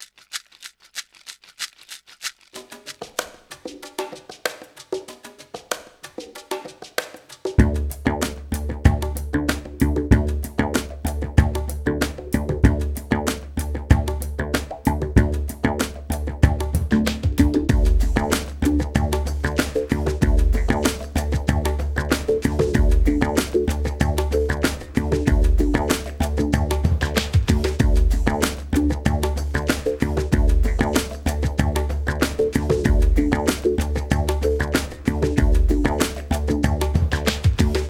Ritmo de percusión 2 (bucle)
membranófono
tambor
africano
percusión
bongó
conga
latino
sintetizador